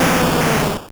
Cri de Kangourex dans Pokémon Or et Argent.